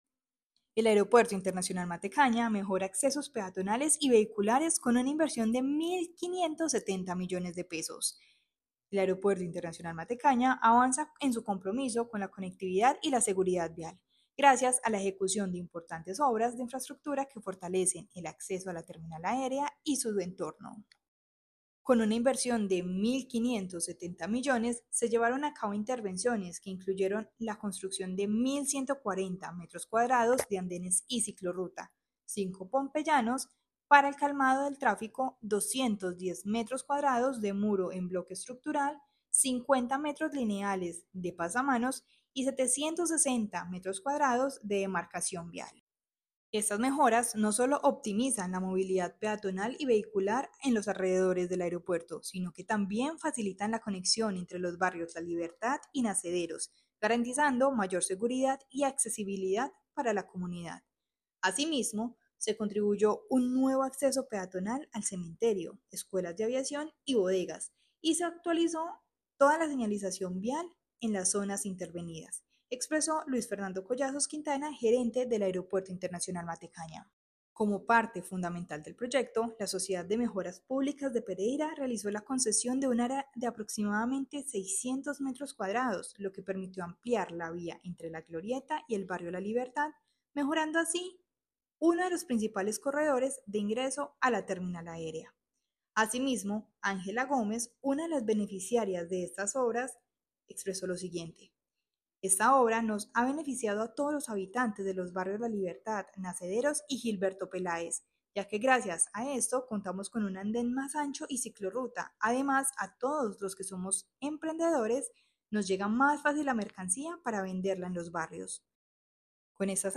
NOTA DE AUDIO OBRAS VIALES Y PEATONALES DEL AIM